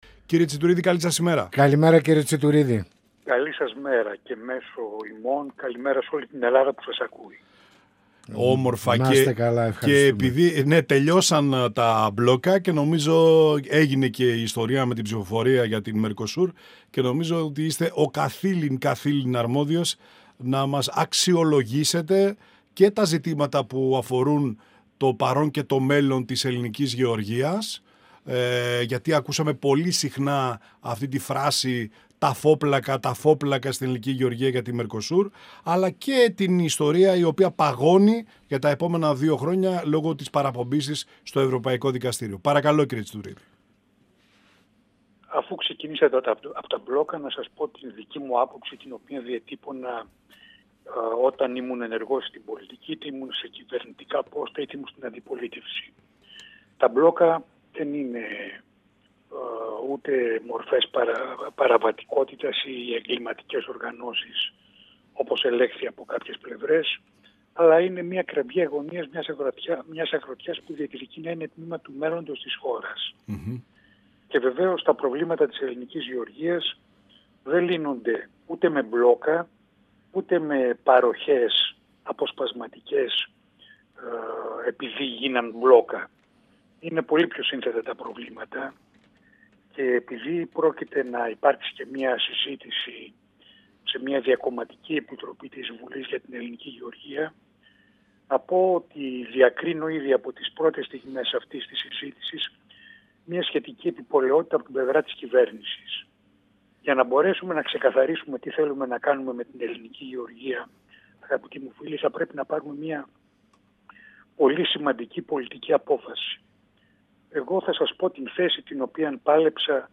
Στην απόφαση του Ευρωκοινοβουλίου να μπλοκάρει τη συμφωνία E.E.- Mercosur, αποστέλλοντας το κείμενο της στο Ευρωπαϊκό Δικαστήριο, τις επιπτώσεις από την εφαρμογή της, στους Έλληνες γεωργούς και κτηνοτρόφους, τη ζωτική ανάγκη συγκρότησης Εθνικής Στρατηγικής για τον πρωτογενή τομέα της οικονομίας και την περιφερειακή Ανάπτυξη, για να μην ερημώσουν τα χωριά και η ύπαιθρος αναφέρθηκε ο π. Υπουργός Αγροτικής Ανάπτυξης και Τροφίμων – Στέλεχος της Ευρωπαϊκής Επιτροπής Σάββας Τσιτουρίδης, μιλώντας στην εκπομπή «Πανόραμα Επικαιρότητας» του 102FM της ΕΡΤ3.
Συνεντεύξεις